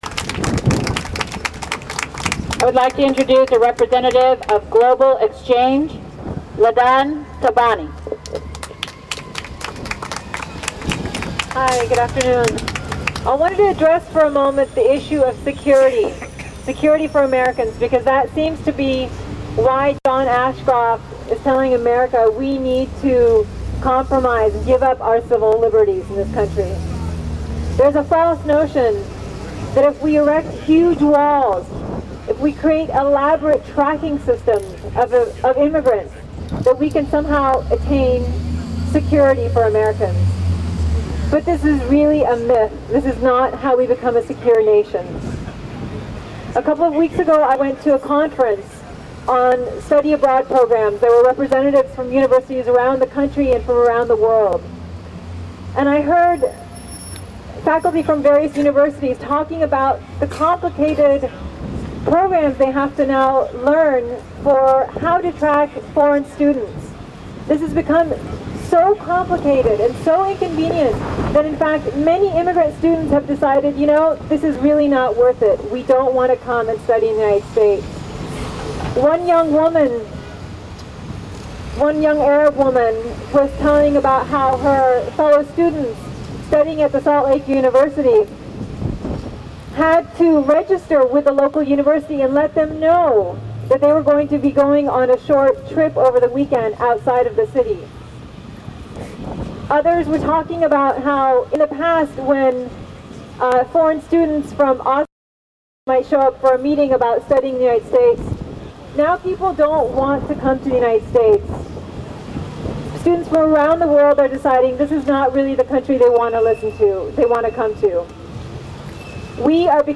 This footage is from the protest in front of the INS building that took place from noon to 1pm at 444 Washington Street in San Francisco on June 13, 2003.